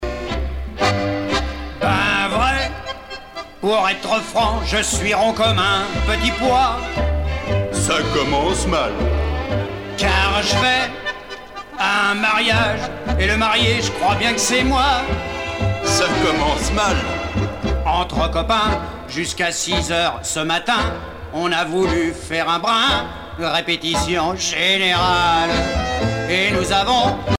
tango musette
Pièce musicale éditée